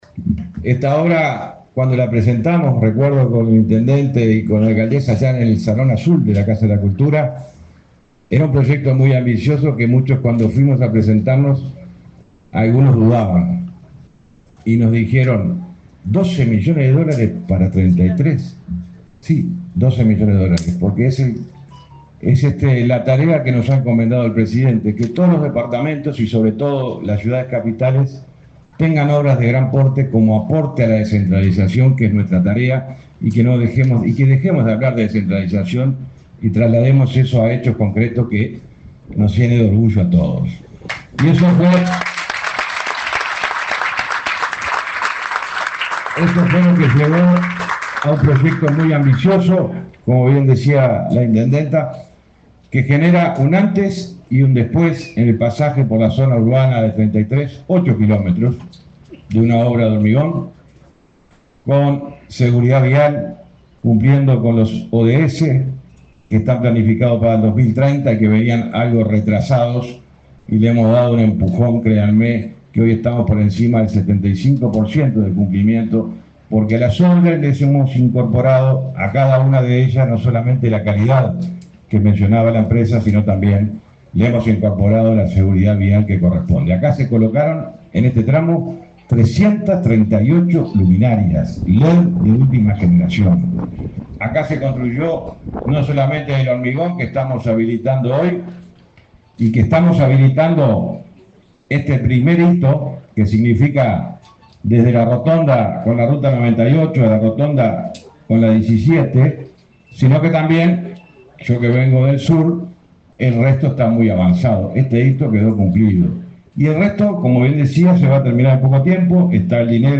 Palabras del ministro de Transporte, José Luis Falero
El ministro de Transporte, José Luis Falero, participó, este jueves 27, en la inauguración de obras de rehabilitación de ruta n.° 8 en el departamento